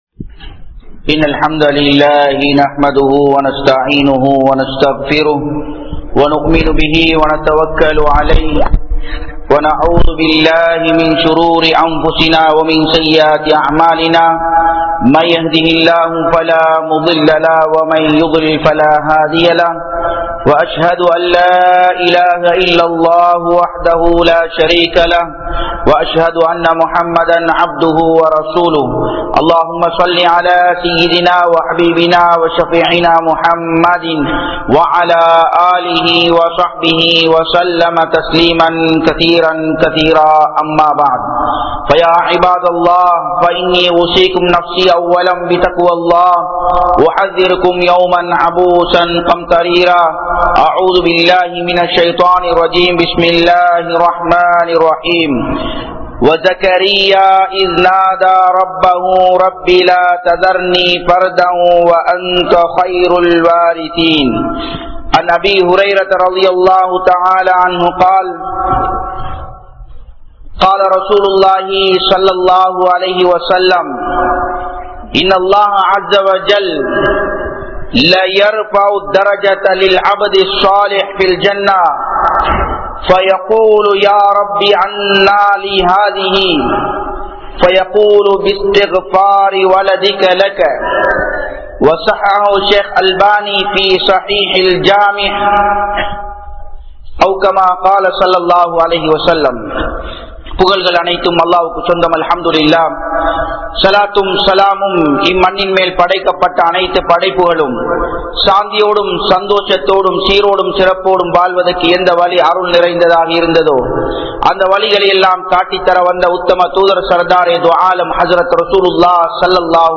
Saalihaana Kulanthai Veanduma? (ஸாலிஹான குழந்தை வேண்டுமா?) | Audio Bayans | All Ceylon Muslim Youth Community | Addalaichenai
Japan, Nagoya Port Jumua Masjidh